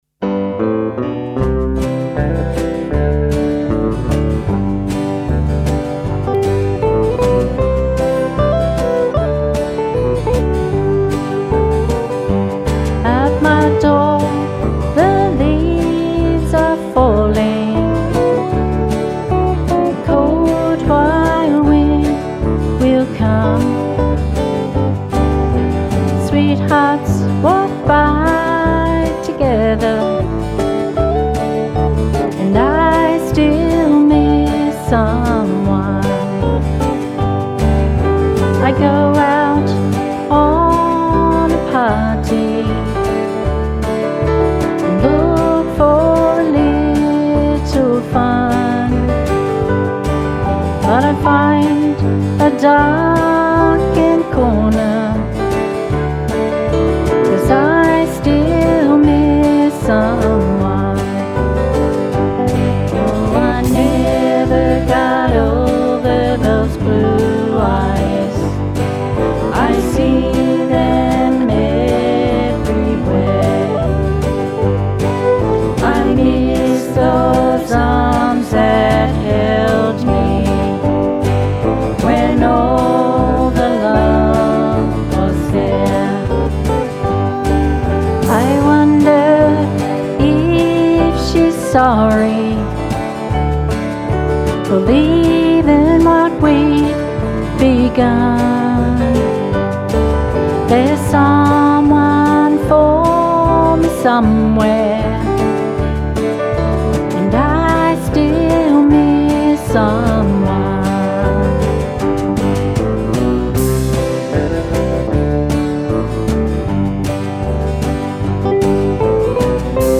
Now an independent country music singer-songwriter
guitar
Sung straight from the heart